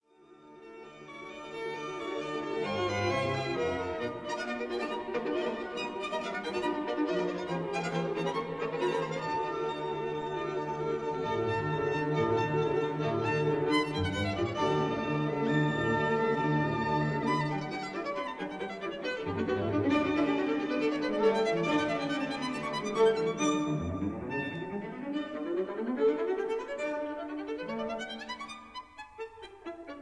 violins
violas
cellos